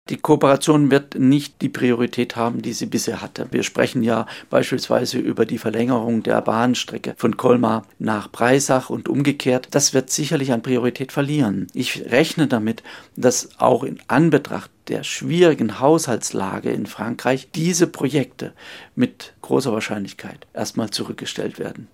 Politikwissenschaftler über mögliche Auswirkungen